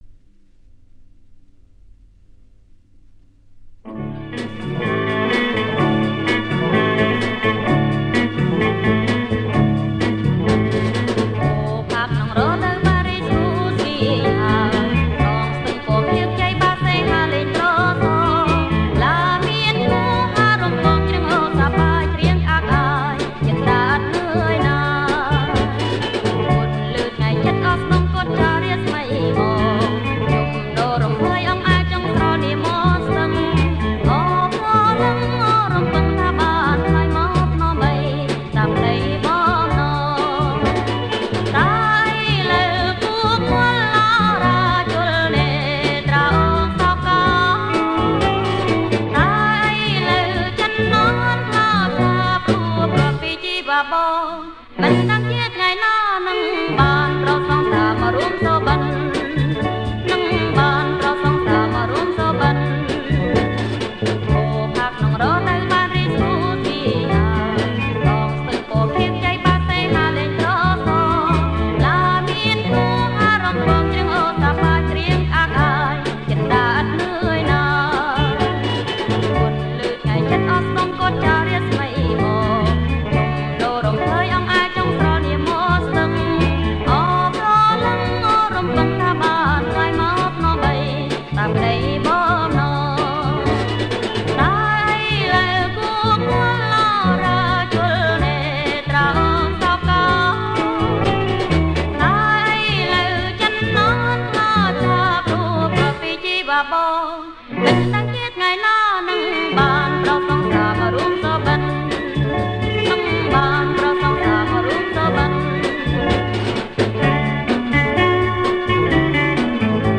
• ប្រគំជាចង្វាក់ Jerk
ប្រគំជាចង្វាក់ Jerk